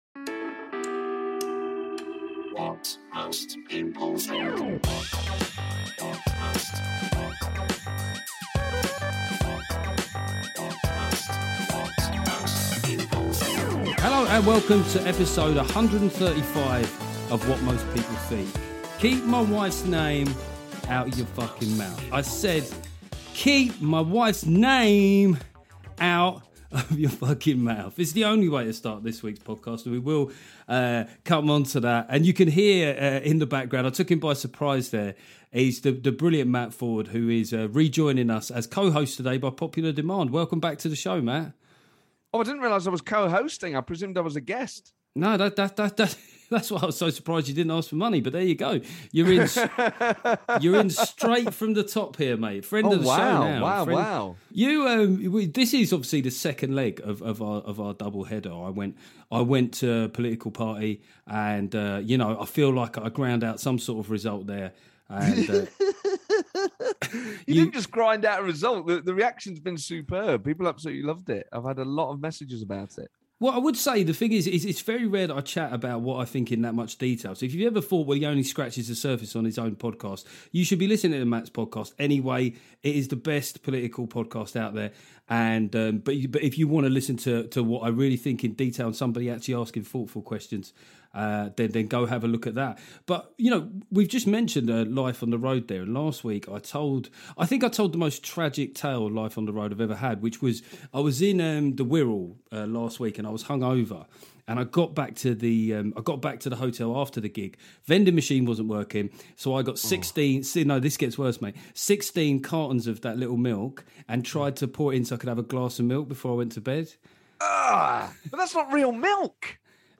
The brilliant Matt Forde co-hosts this week. We talk about comedians getting slapped, politicians getting slapped with fines and whether Joe Biden needs a light one to wake up.